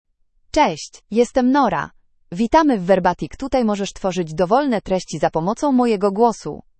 Nora — Female Polish (Poland) AI Voice | TTS, Voice Cloning & Video | Verbatik AI
NoraFemale Polish AI voice
Nora is a female AI voice for Polish (Poland).
Voice sample
Female
Nora delivers clear pronunciation with authentic Poland Polish intonation, making your content sound professionally produced.